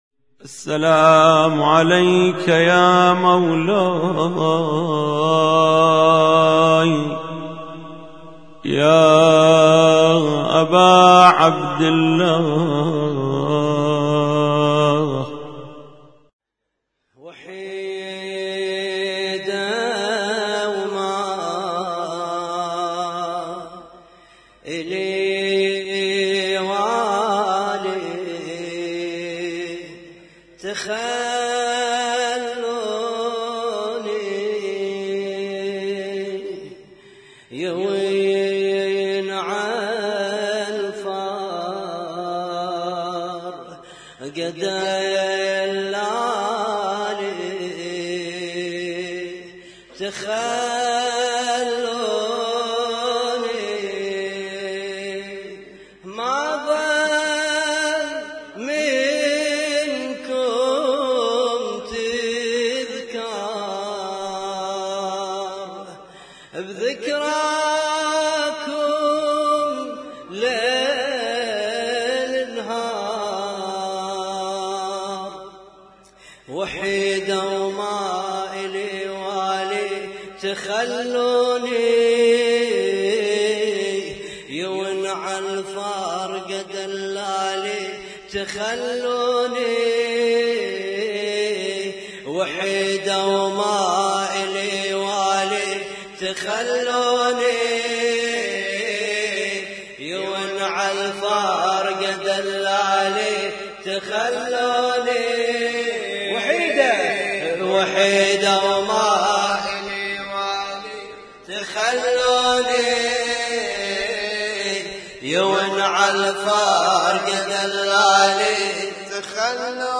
لطم مشترك ليلة 3 محرم 1436